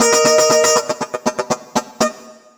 120FUNKY15.wav